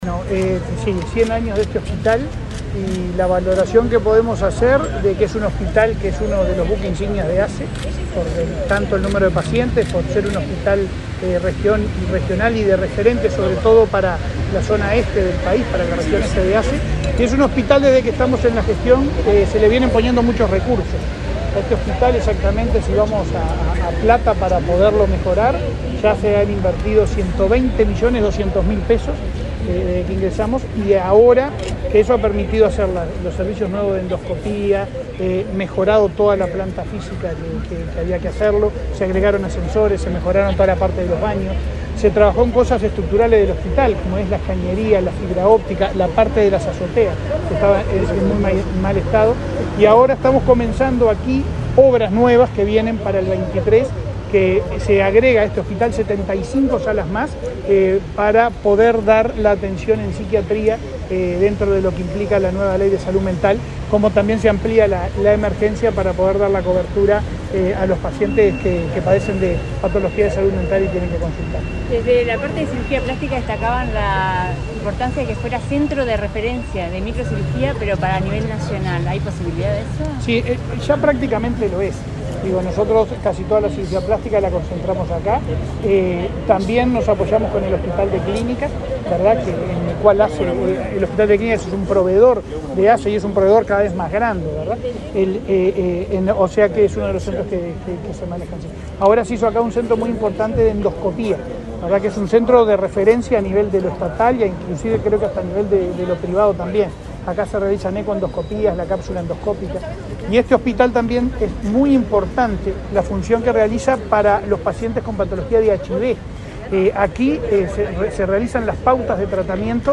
Declaraciones del presidente de ASSE, Leonardo Cipriani
El presidente de la Administración de los Servicios de Salud del Estado (ASSE), Leonardo Cipriani, participó de la celebración por los 100 años del